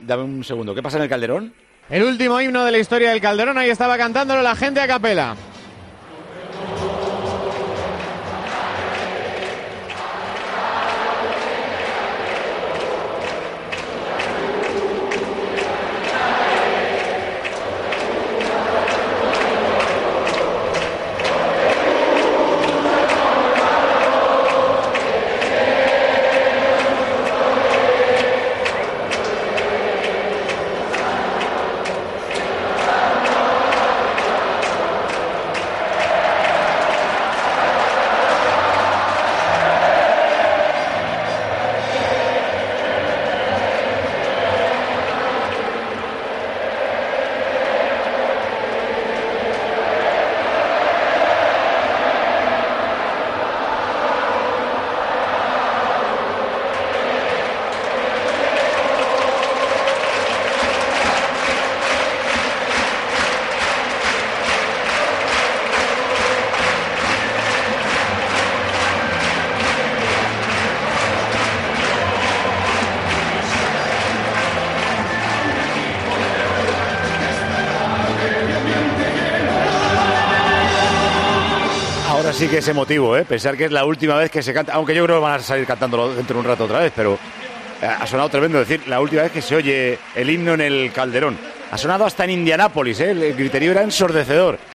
Así sonó, por última vez, el himno del Atlético en el Calderón